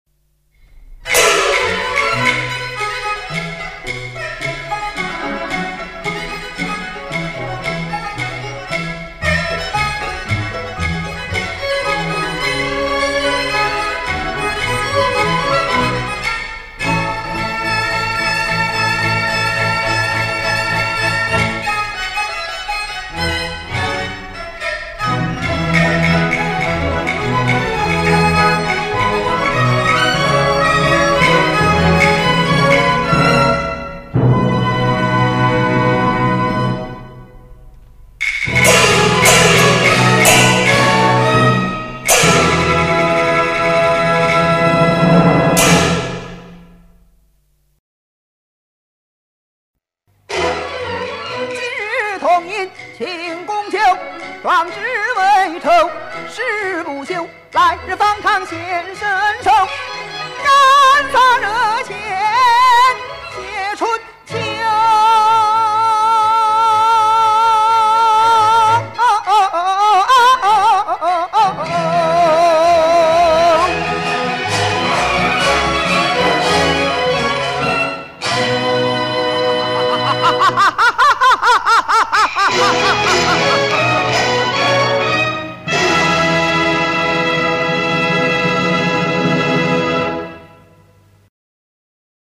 立体伴奏为1992年录制